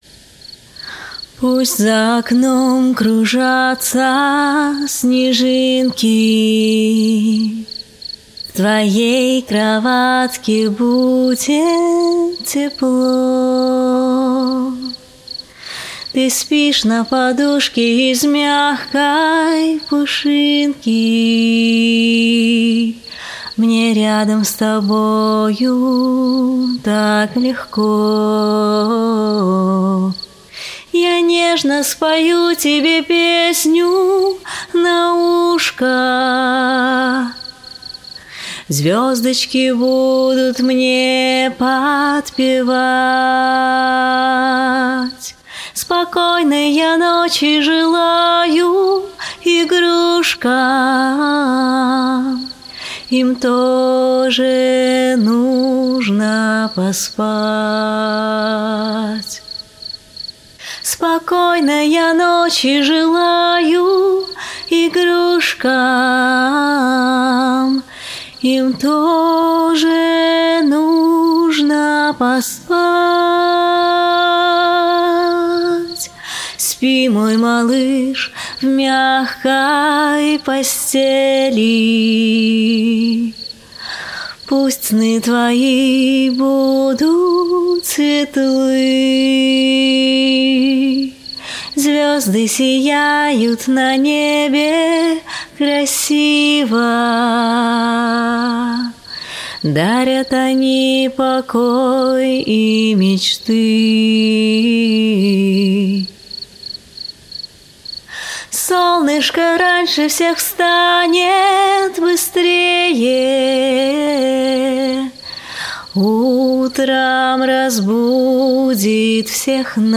🎶 Детские песни / Колыбельные песни